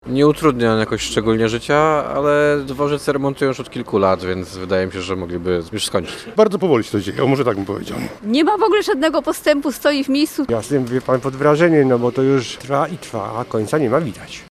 Pasażerowie Szybkiej Kolei Miejskiej w Gdyni są pod wrażeniem, że remont można prowadzić aż tak długo: